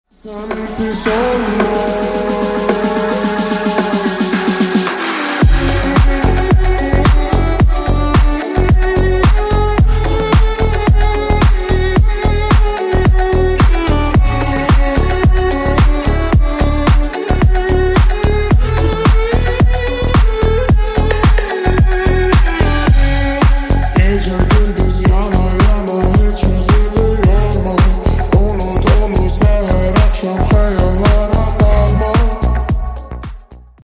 Клубные Рингтоны » # Восточные Рингтоны
Танцевальные Рингтоны